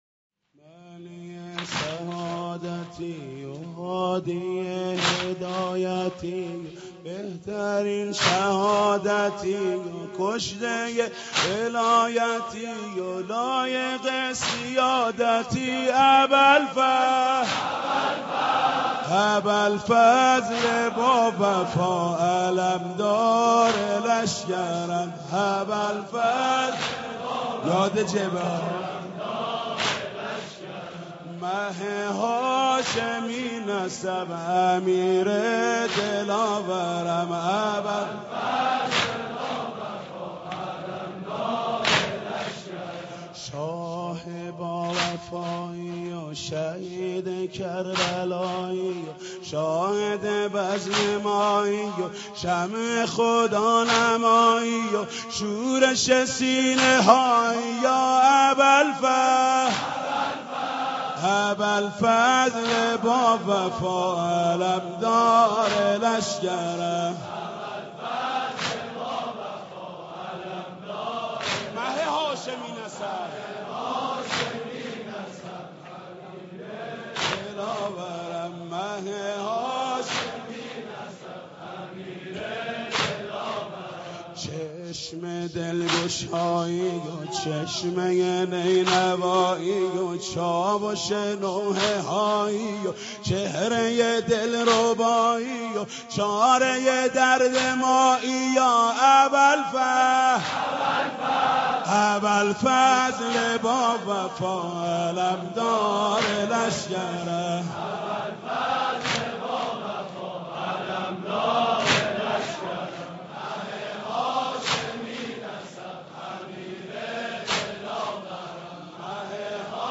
اشعار ابالفضل العباس علیه السلام به همراه سبک با صدای حاج محمود کریمی/واحد -( معنی سعادتی و هادی هدایتی و )